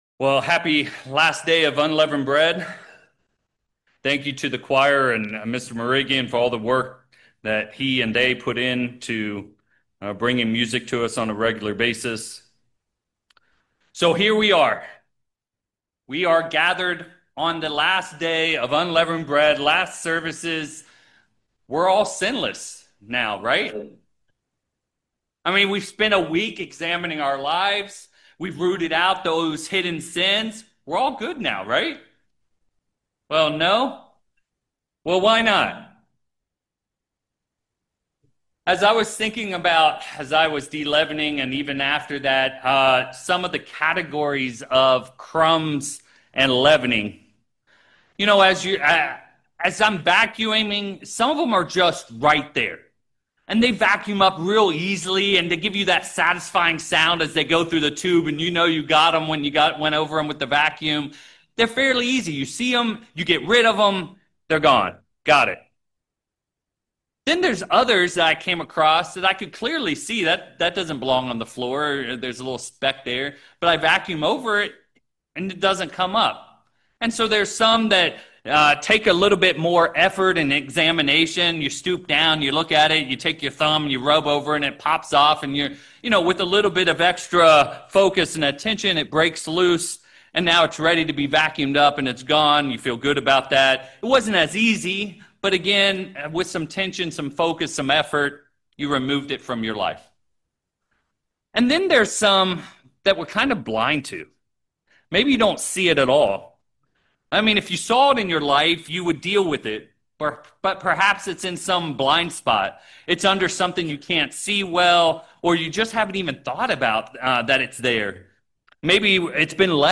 4/8/26 This sermon emphasizes that sin is not always obvious or easily removed—like crumbs in the floor board of cars, some struggles become deeply embedded in the fabric of our lives, subtly taking mastery over us. This message calls on Christians to recognize what truly rules them and, through God’s power and a process of honest surrender, examination, and transformation, reclaim mastery so that only God reigns in their lives.